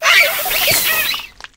grafaiai_ambient.ogg